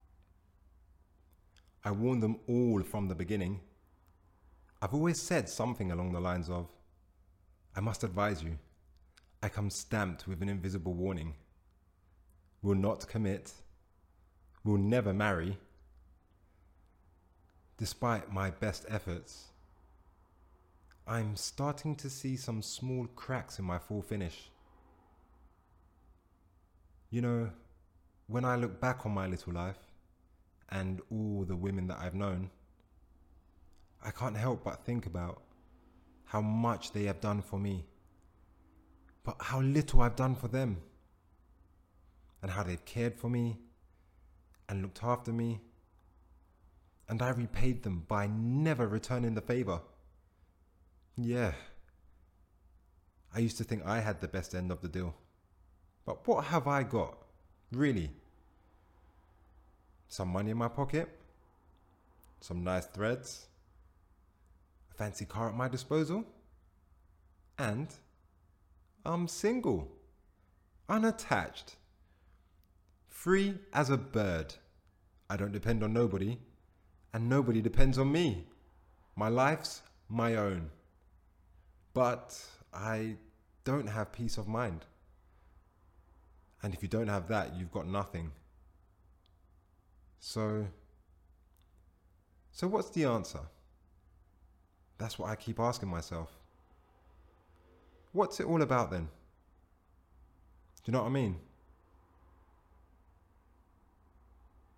VOICE REEL
British Actor. highly skilled at kick boxing.